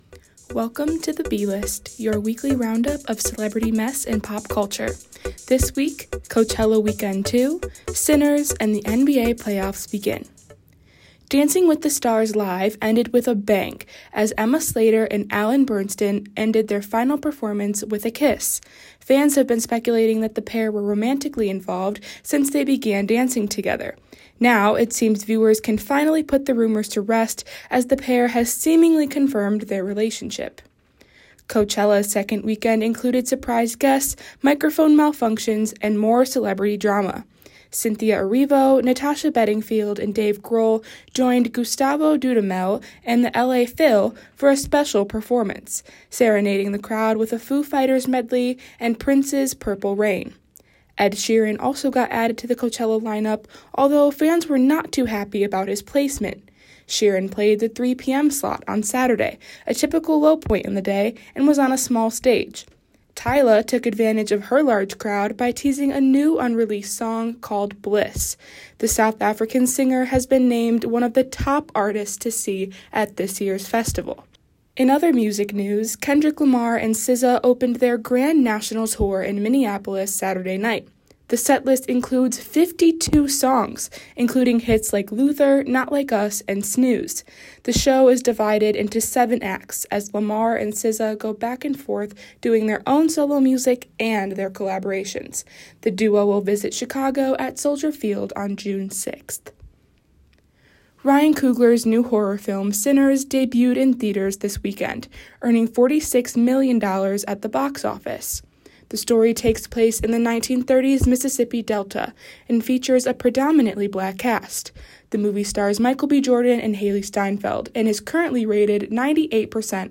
Your favorite weekly pop culture segment is back.
Theme music is from MixKit “What What?! Wowow!” by Michael Ramir C.